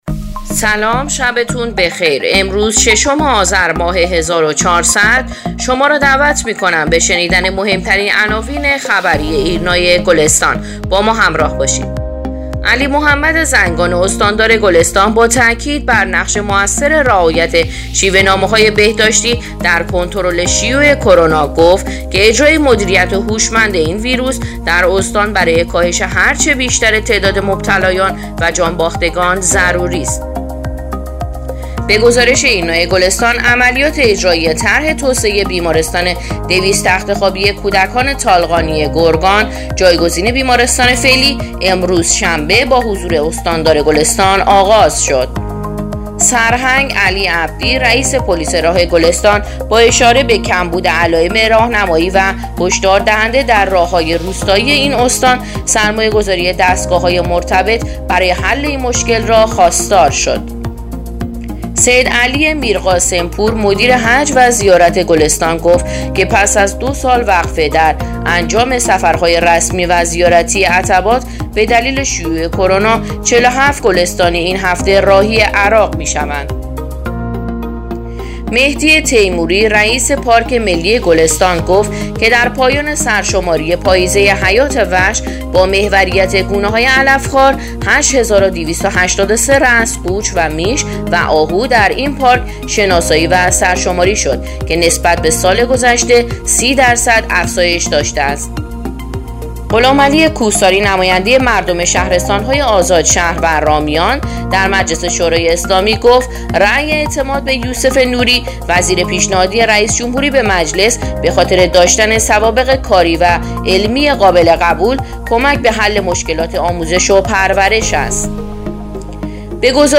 پادکست/اخبار شامگاهی ششم آذر ایرنا گلستان